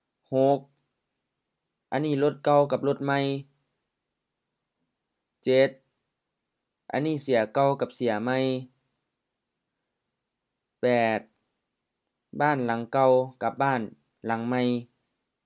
IsaanPronunciationTonesThaiEnglish/Notes